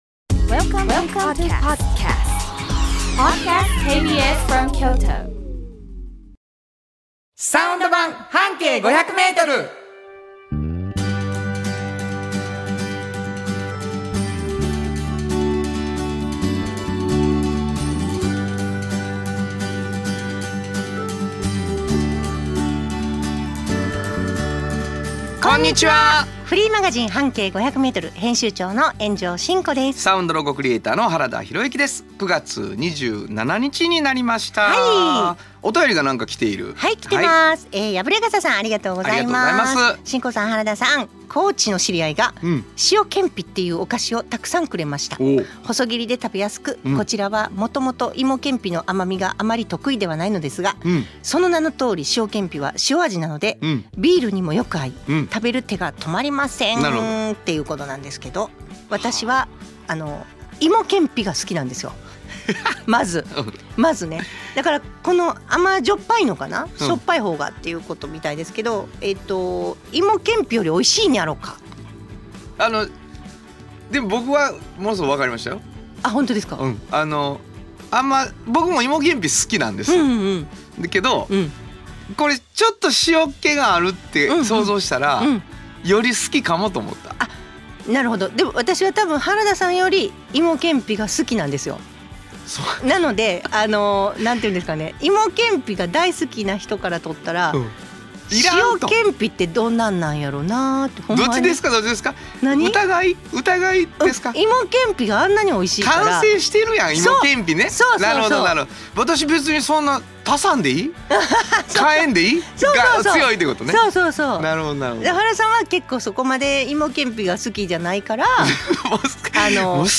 【先週の土曜日の「サウンド版ハンケイ500m」】 9月27日（土）の放送はこちら！